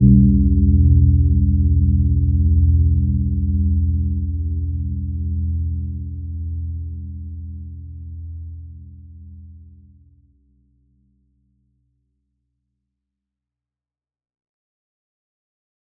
Gentle-Metallic-2-E2-mf.wav